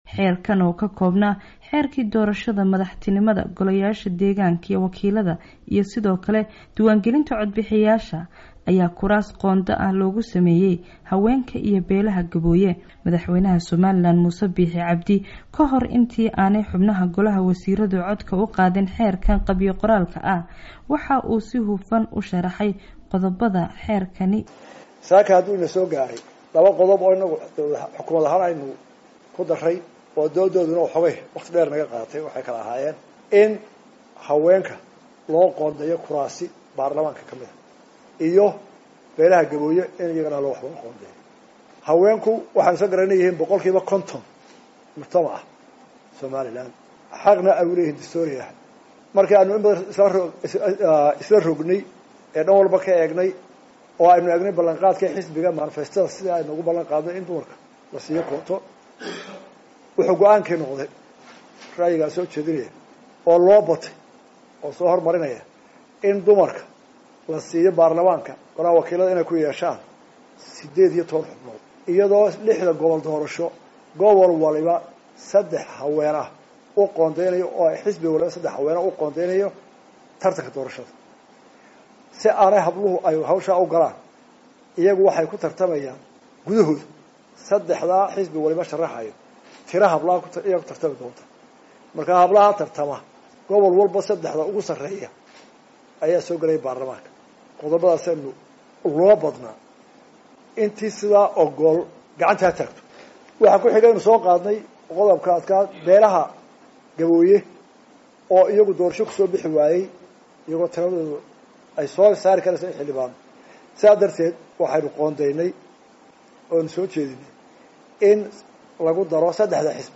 Wariyaha VOA